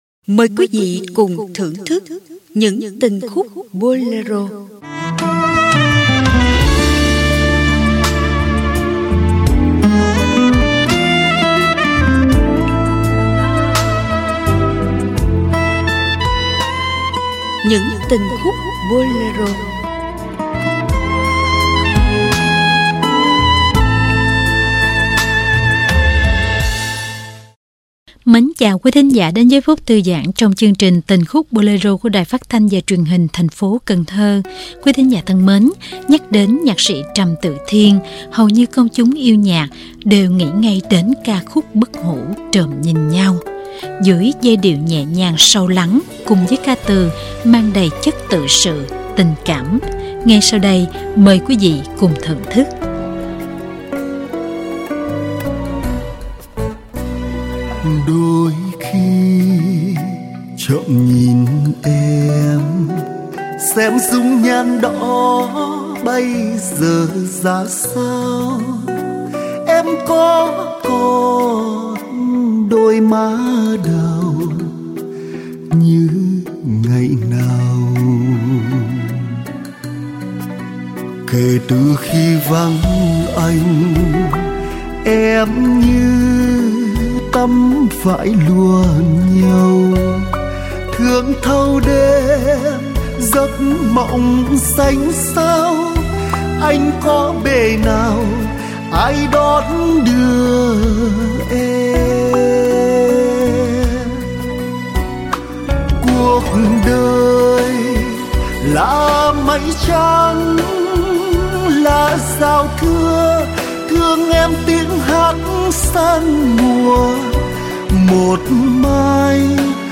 Ca nhạc